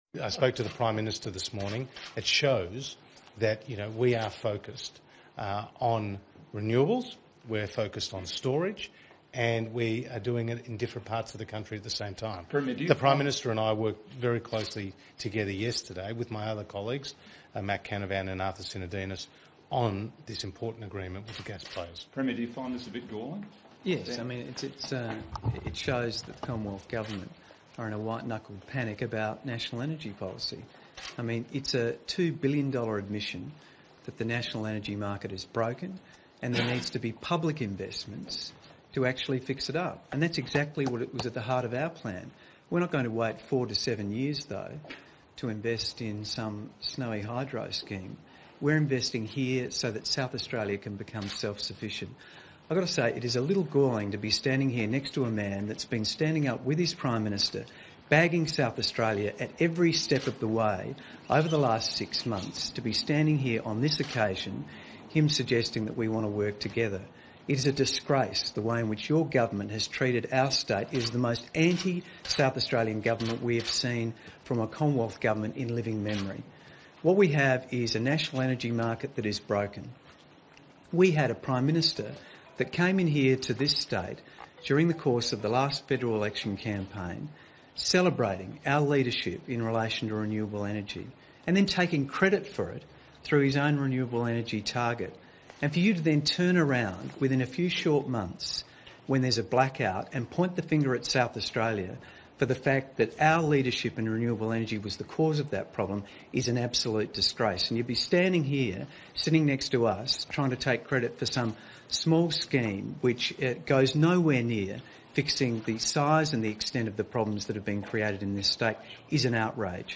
Jay Weatherill slams federal government on live television